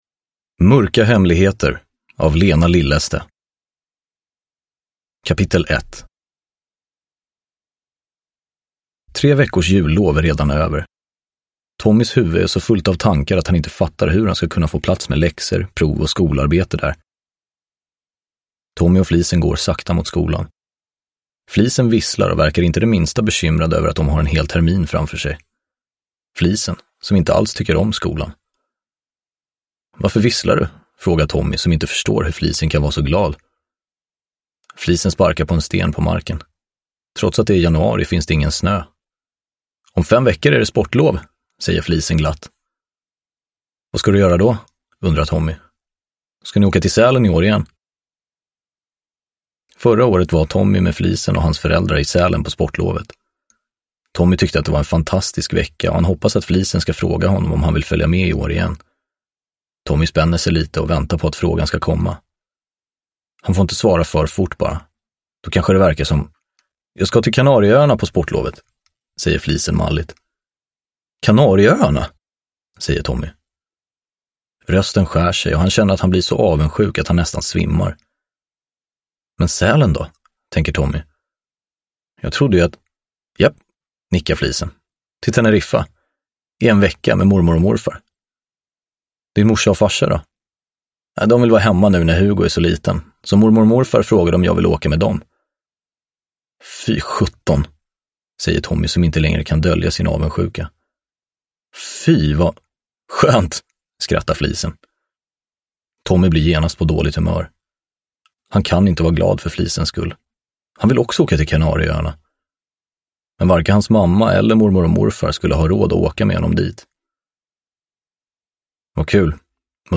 Mörka hemligheter – Ljudbok – Laddas ner